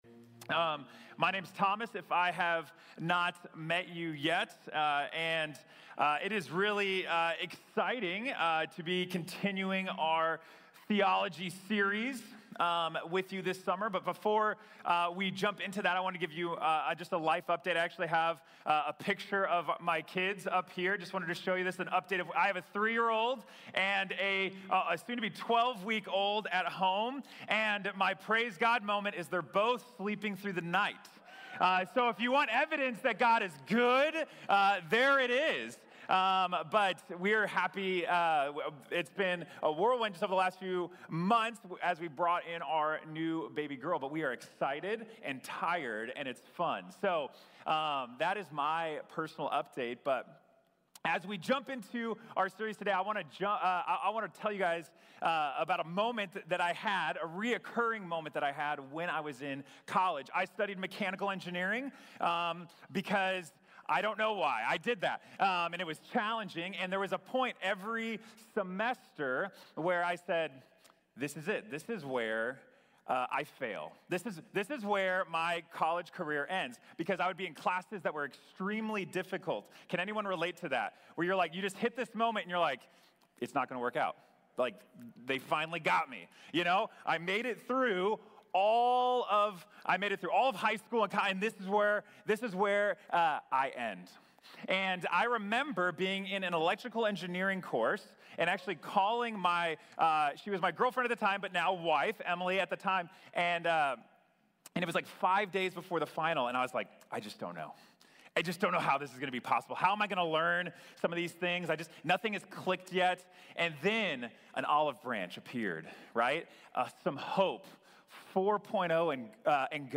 Angelología y Demonología | Sermón | Iglesia Bíblica de la Gracia